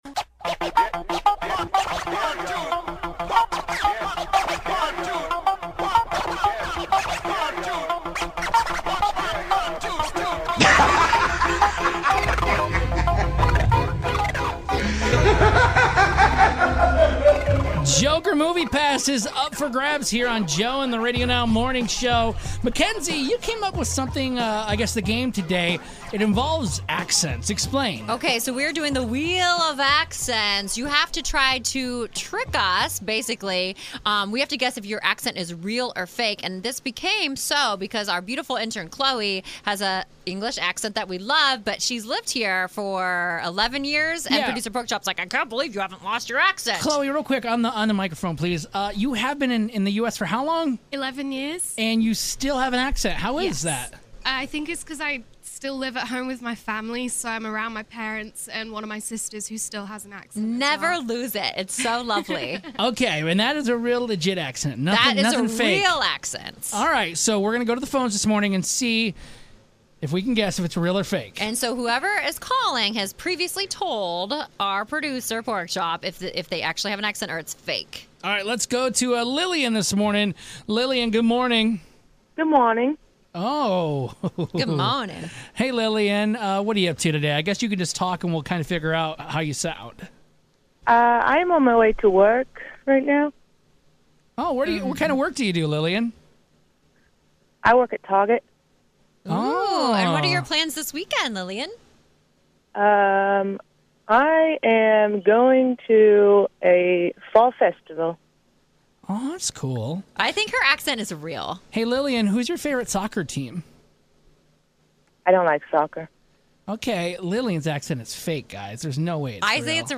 GAME: Accents Real or Fake?
When have to guess if your accent is real or if it's fake & if you trick us then you win tickets to go see the Joker.